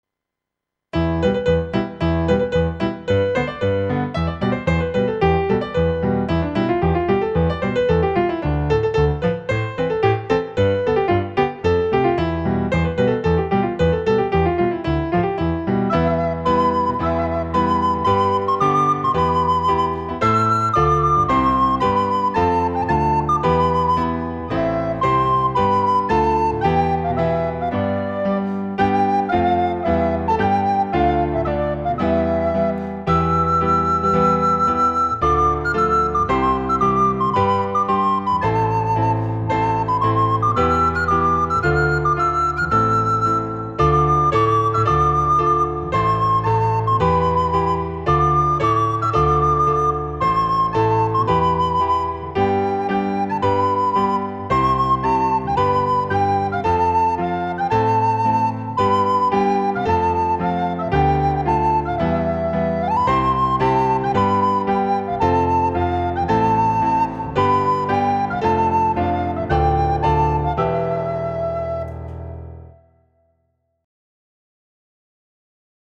۲. فایل صوتی MP3 اجرای مرجع
• اجرای دقیق و وفادار به نت‌های تنظیم‌شده
• کمک به درک تمپو، اوج‌گیری‌ها و فضای حماسی قطعه
ایرانی